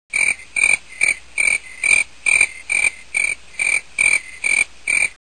crickets.wav